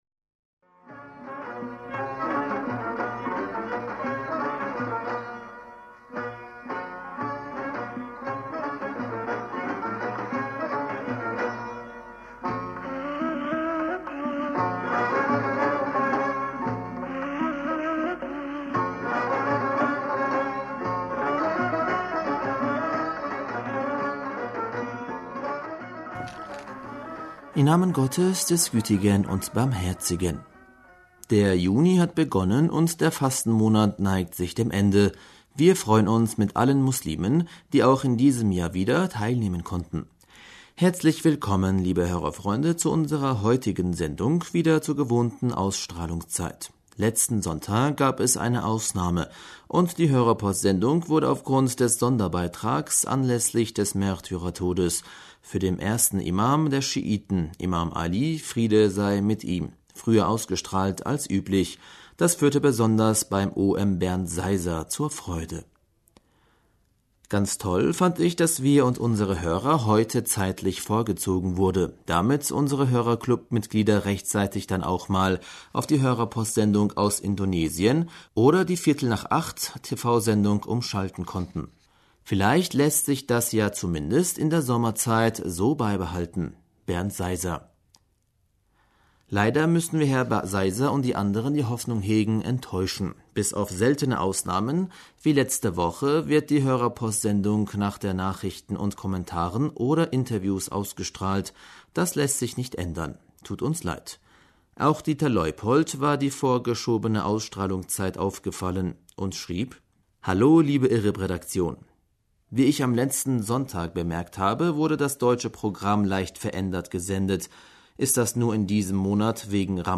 Hörerpostsendung 02.06.2019 Hörerpostsendung am 02. Juni 2019 - Bismillaher rahmaner rahim - Der Juni hat begonnen und der Fastenmonat neigt sich dem...